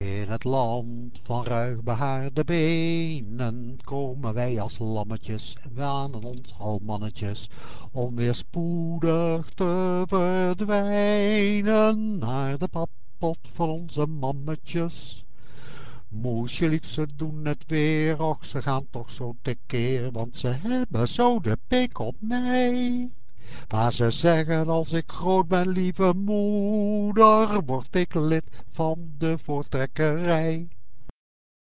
Dat was het stamlied, gelukkig niet gespeend van zelfironie.
stamlied.rm